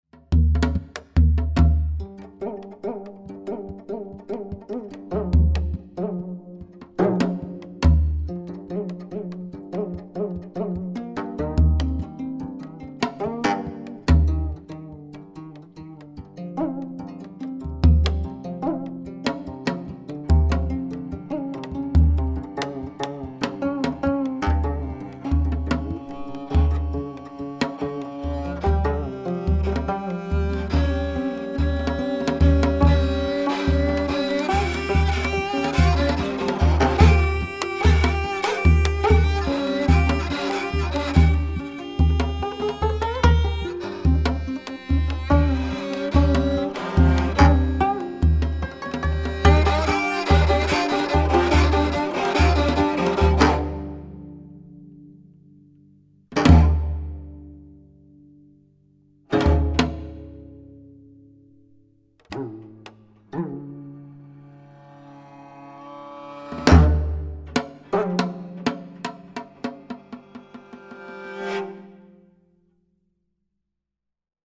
gayageum, ajaeng & janggu
산조 가야금, 산조 아쟁, 장구